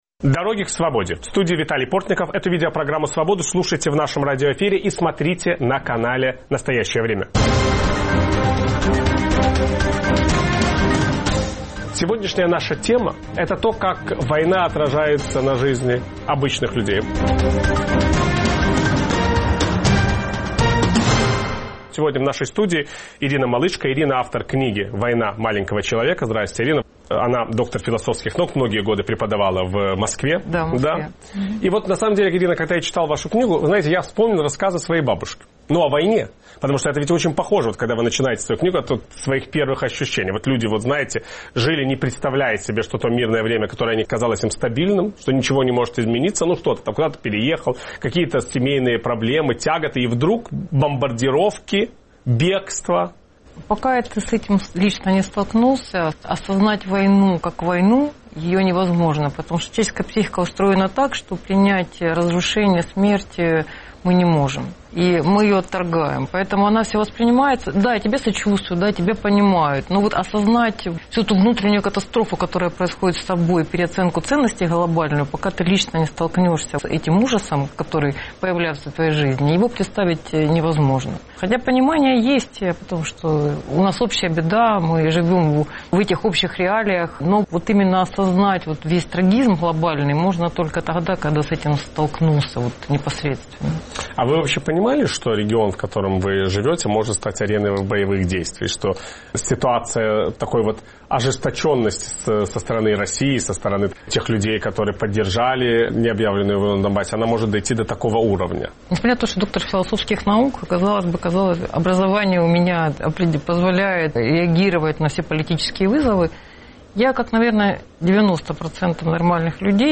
Виталий Портников беседует с автором